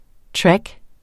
Udtale [ ˈtɹag ]